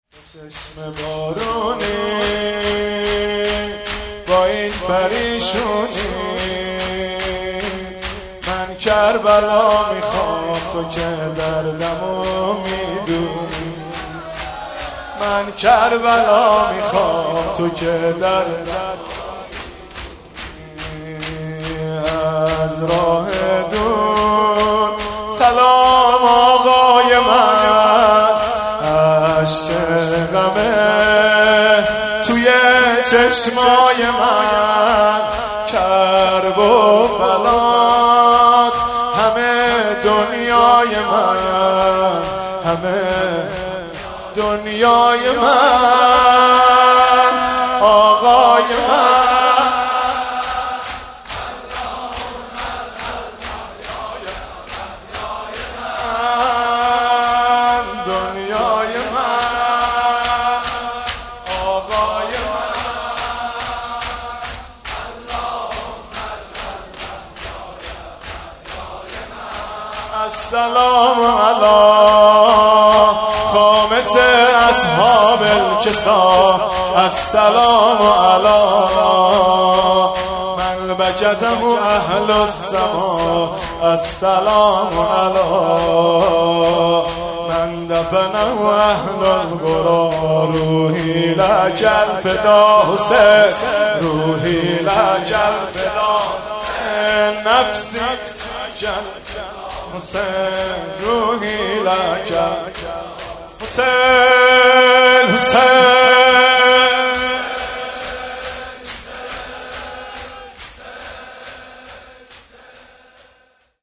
مداحی شب جمعه